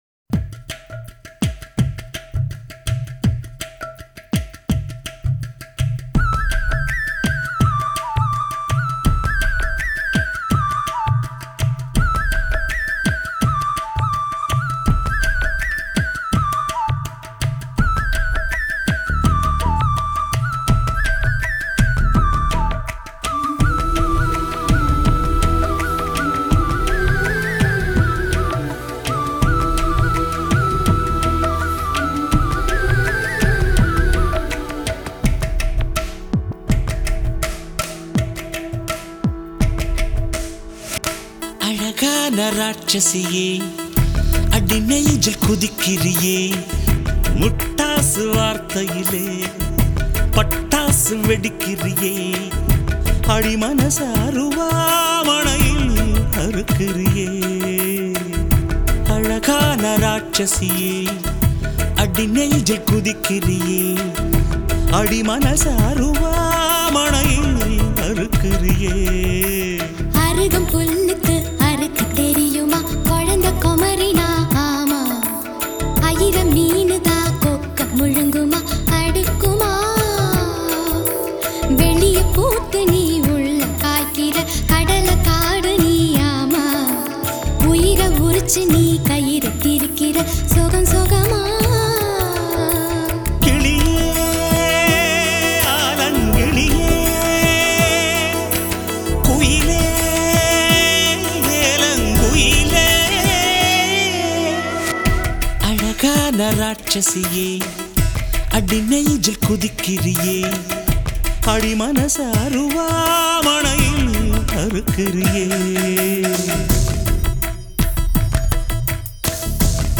Recorded Panchathan Record Inn